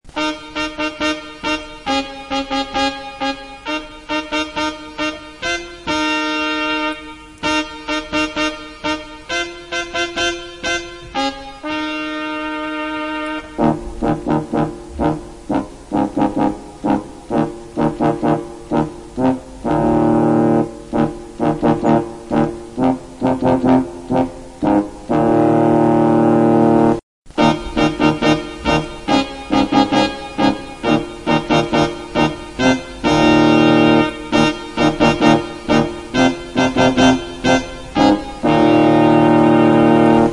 Download Medieval sound effect for free.